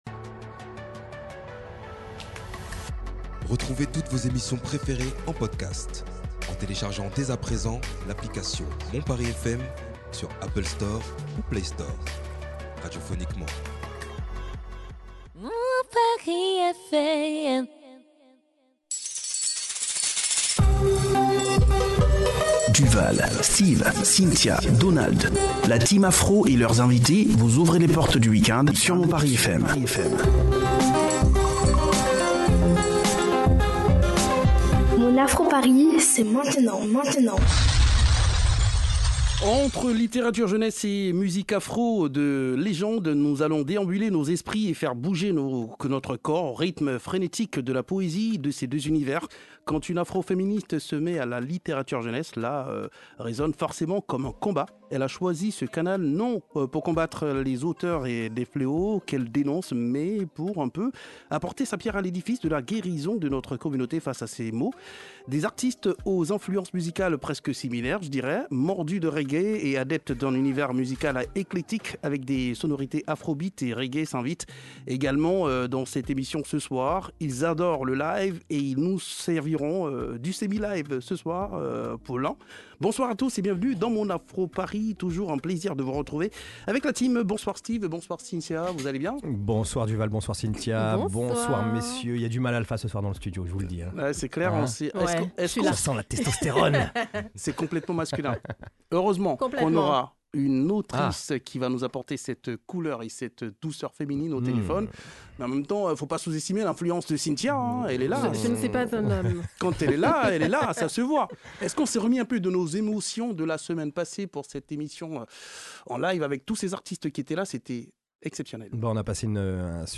sonorités afrobeat et reggae, ils adorent le live et le semi-live s'invite dans votre émission.